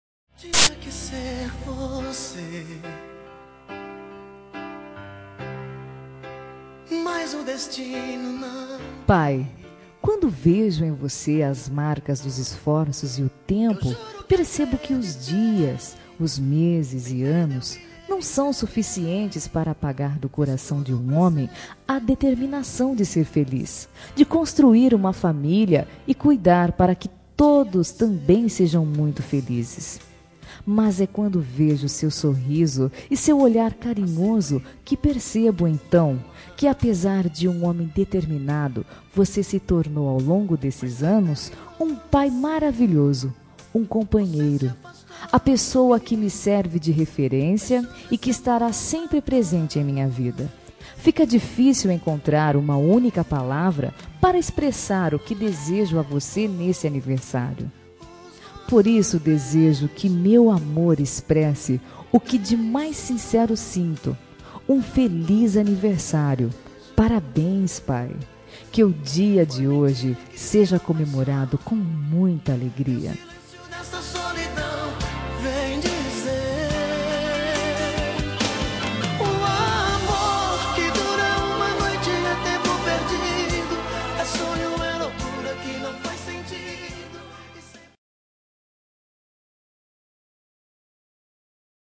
Telemensagem de Aniversário de Pai – Voz Feminina – Cód: 1478